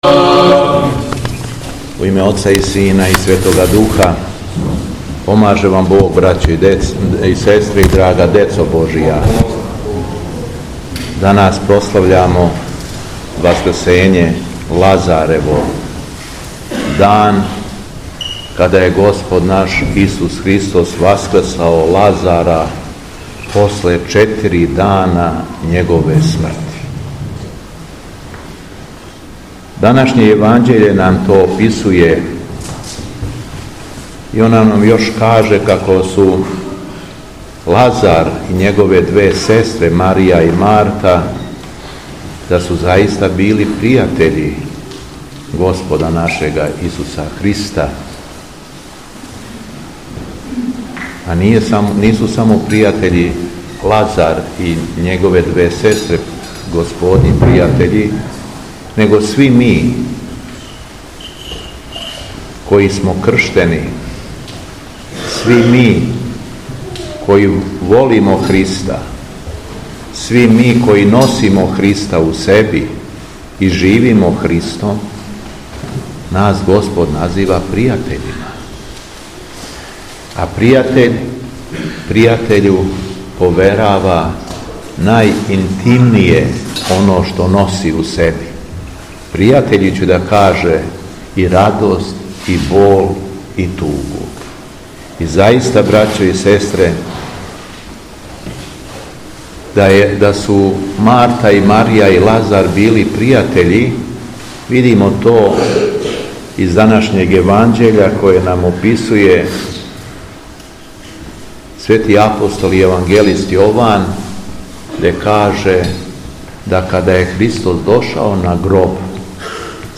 СВЕТА АРХИЈЕРЕЈСКА ЛИТУРГИЈА У КРАГУЈЕВАЧКОМ НАСЕЉУ ШУМАРИЦЕ НА ЛАЗАРЕВУ СУБОТУ - Епархија Шумадијска
Беседа Његовог Високопреосвештенства Митрополита шумадијског г. Јована